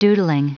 Prononciation du mot doodling en anglais (fichier audio)
Prononciation du mot : doodling